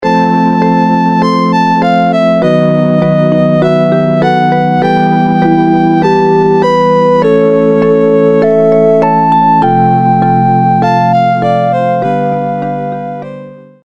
Nokia полифония. Мультики